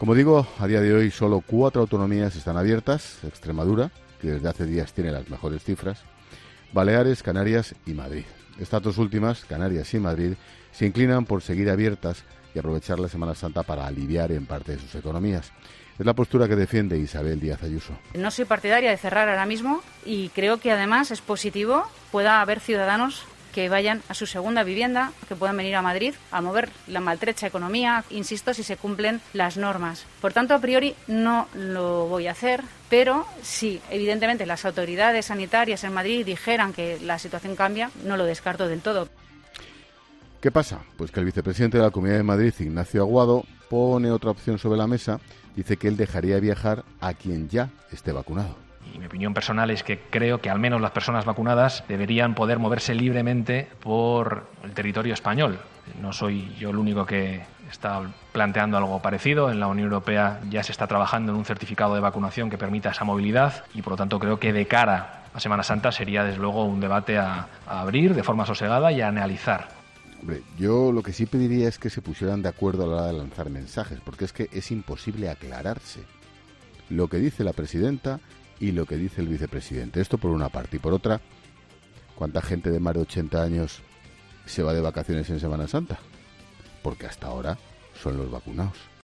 El presentador de 'La Linterna' comenta las últimas declaraciones del vicepresidente de Madrid